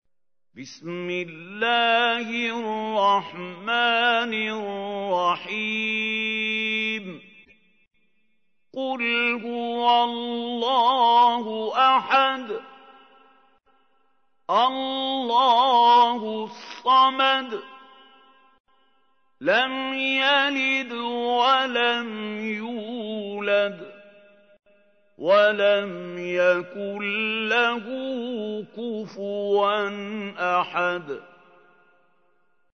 تحميل : 112. سورة الإخلاص / القارئ محمود خليل الحصري / القرآن الكريم / موقع يا حسين